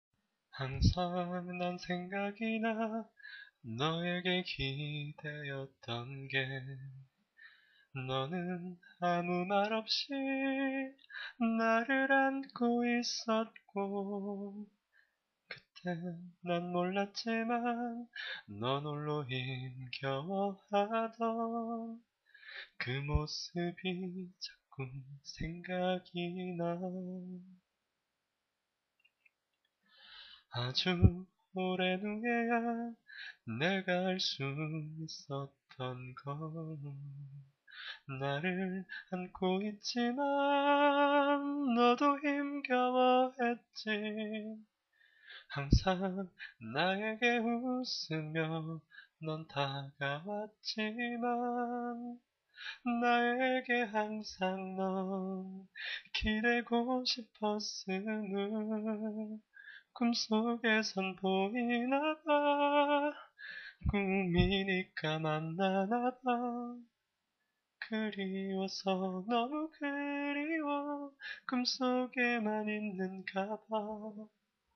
낮춰서 부릅니당..